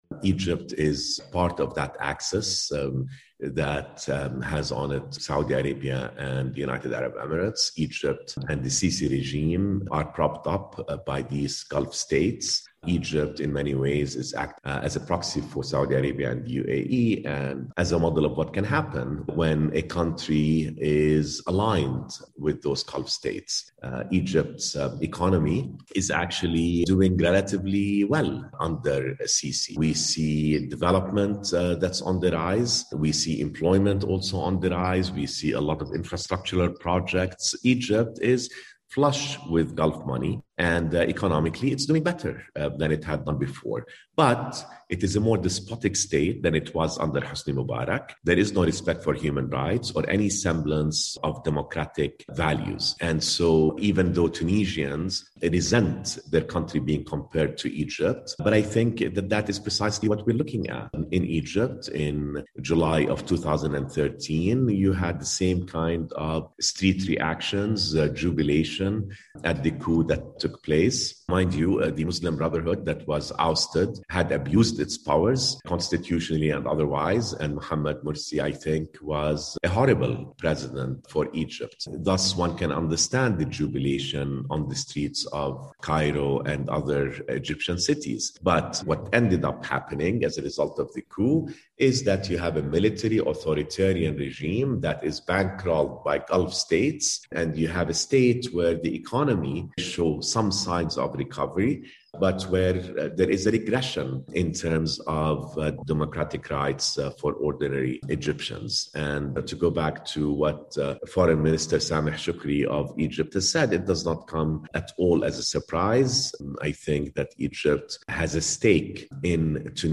Egypt's foreign minister says President Abdel-Fattah el-Sissi supports Tunisian President Kais Saied's move to freeze parliament and take over executive powers. For Africa News Tonight